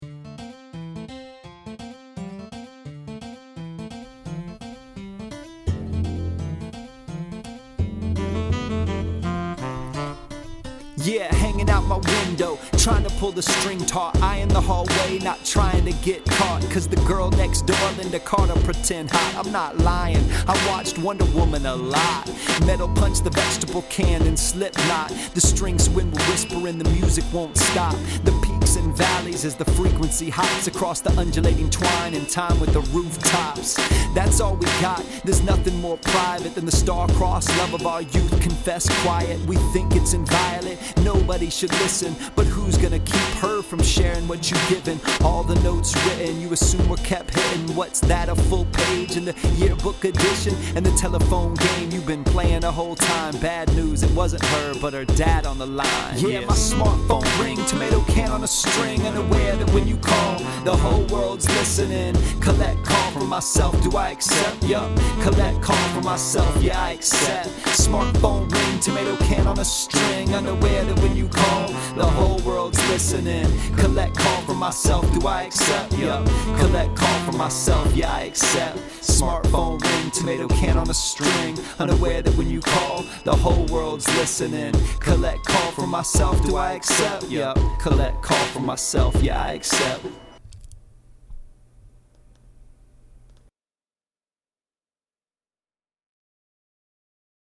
there’s nothing that says “hip hop” like a good case citation.
Thanks for the great instrumental.